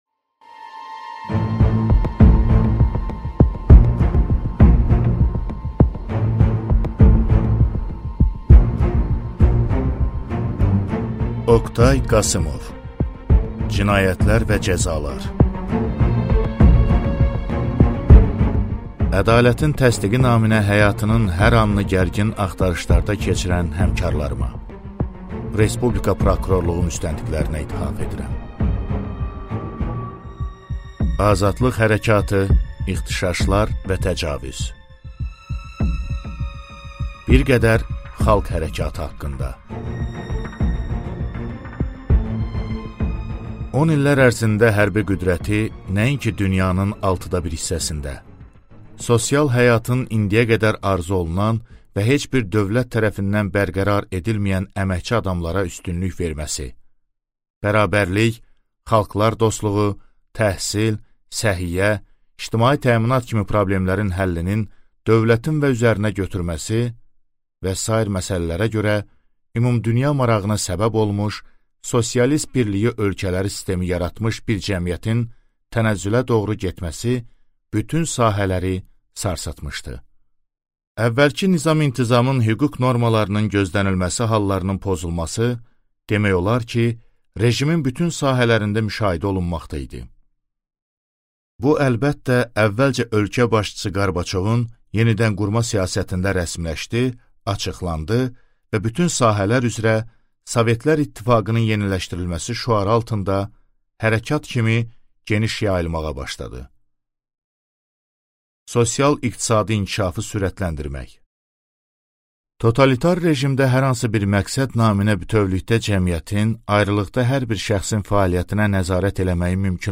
Аудиокнига Cinayətlər və cəzalar | Библиотека аудиокниг